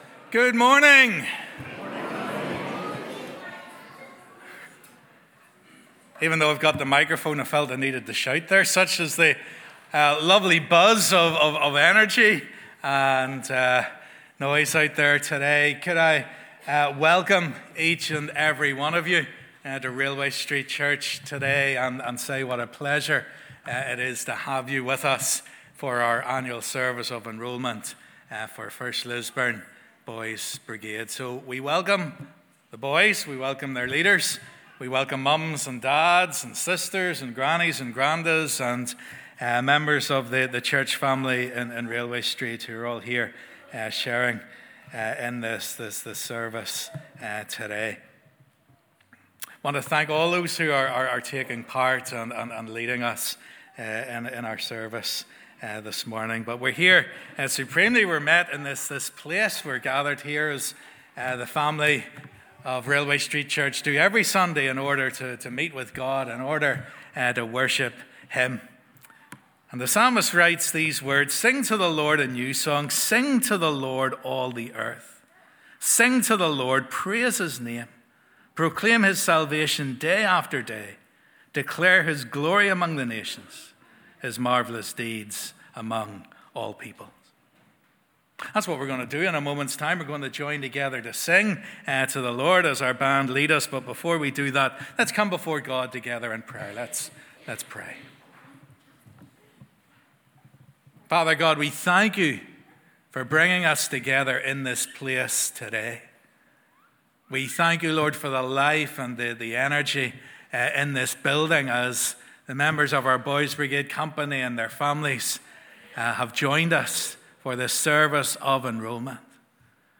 'Annual Service of Enrolment for 1st Lisburn Boys Brigade Company'
This morning we welcome all the boys of our BB Company along with their families who join us for their annual service of enrolment.